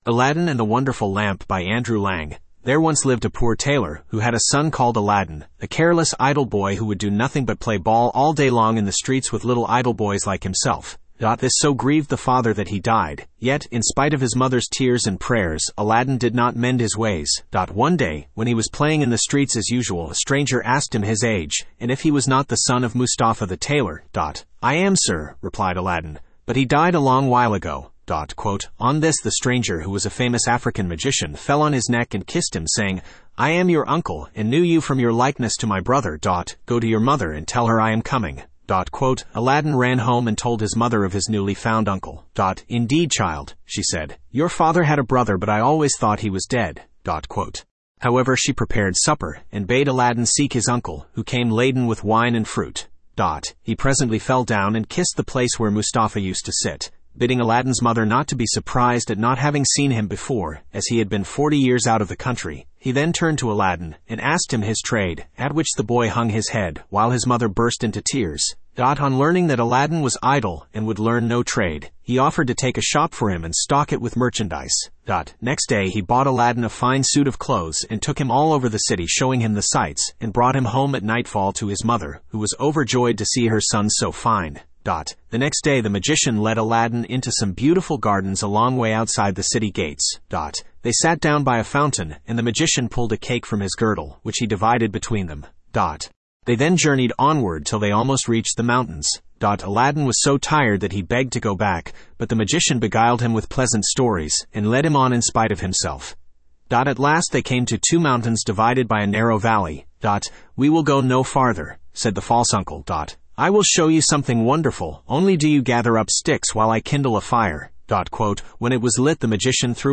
Studio (Male)
aladdin-and-the-wonderful-lamp-en-US-Studio-M-20887cdd.mp3